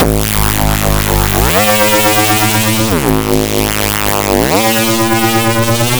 overprocessed serum reese.wav